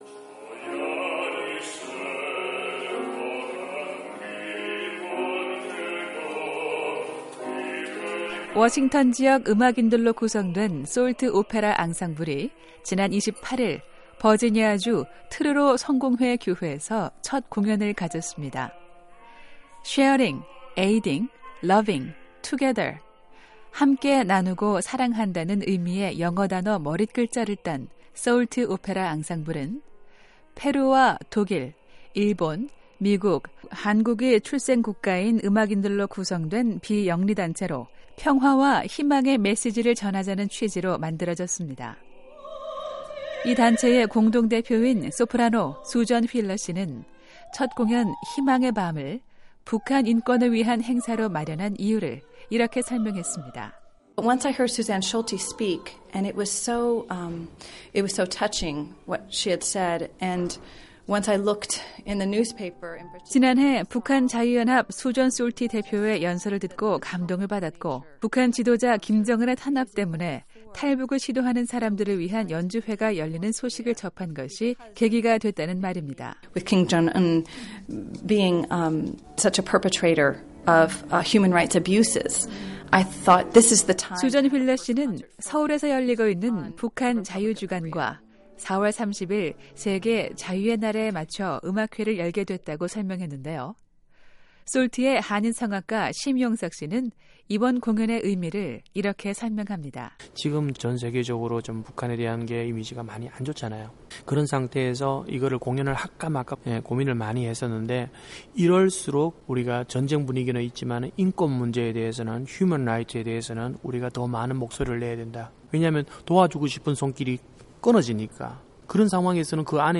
지난 주말 북한 주민들의 인권을 위한 음악회가 열렸습니다. 미국 교회가 주관한 이 음악회에서는 출생국가가 서로 다른 오페라 가수들이 한국의 가곡을 불렀습니다.